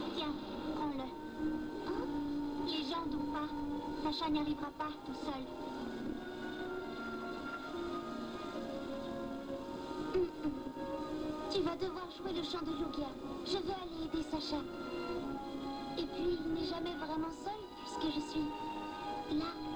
2) Films.
Et elle ajoute ce demi-aveu d'une voix tendre : "Et puis, il n'est jamais vraiment seul, puisque je suis... là !"